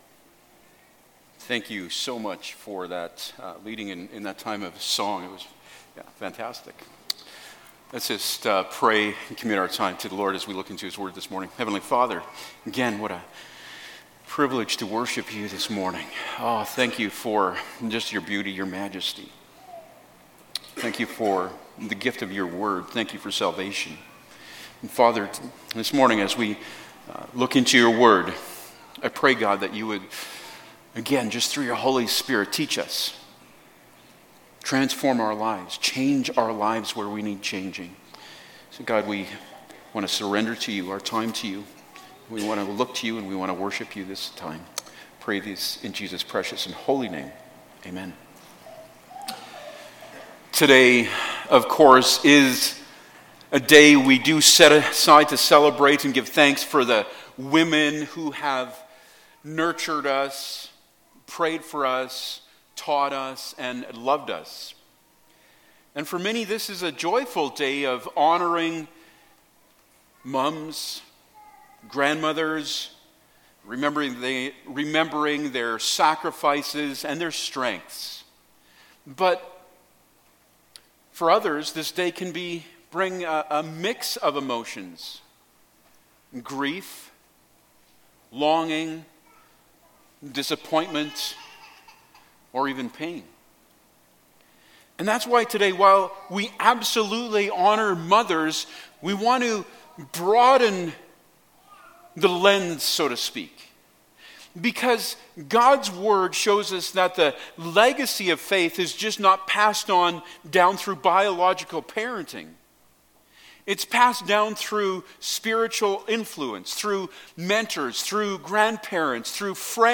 Passage: 2 Timothy 1:5-7 Service Type: Sunday Morning Topics